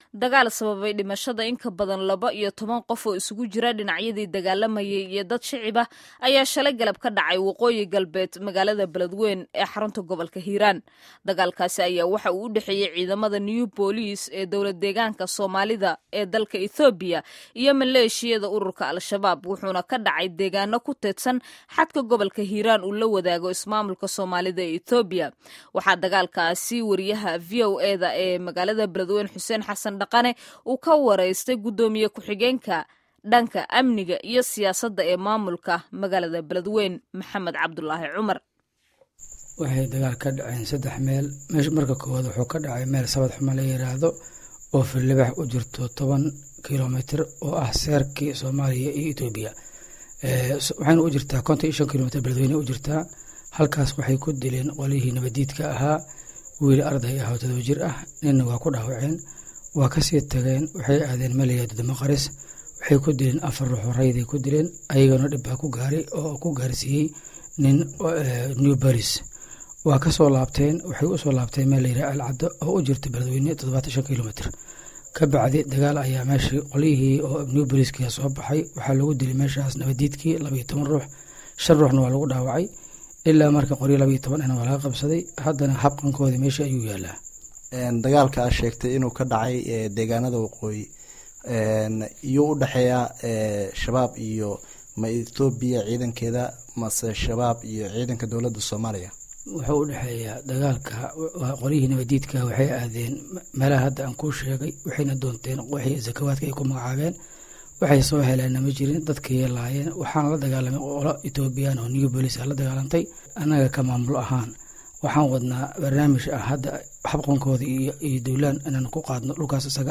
Gudoomiye ku-xigeenka dhanka Amniga iyo Siyaasada ee Maamulka magaalada Baledweyne Max'ed C/laahi Cumar (Cadde) ayaa VOA u sheegay in maleeshiyada Al-Shabaab ay deeganada ka geysteen dhac, dad shacab ahna ay ku laayeen.
Wareysiga Dagaalka Al-shabaab iyo Ethiopia